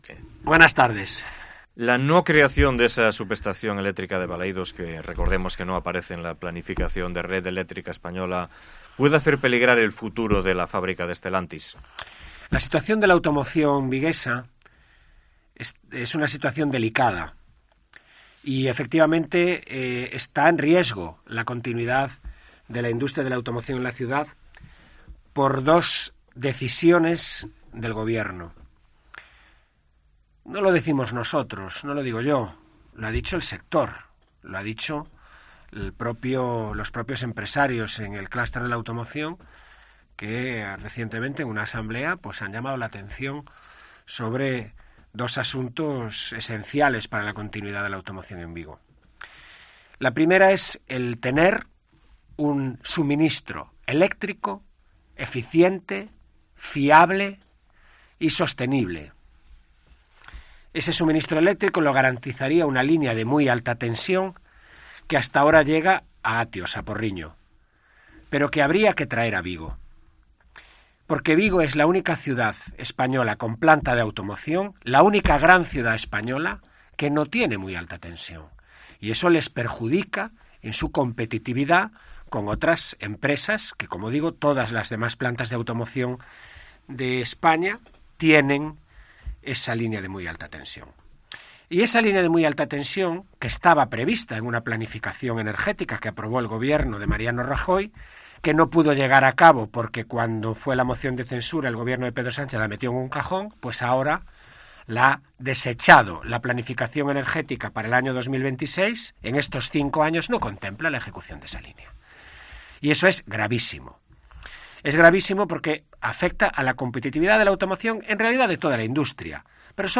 Entrevista al portavoz de los populares en Vigo que también nos habló de la decisión del Concello de ceder la gestión de las oposiciones de la Policía Local a la AGASP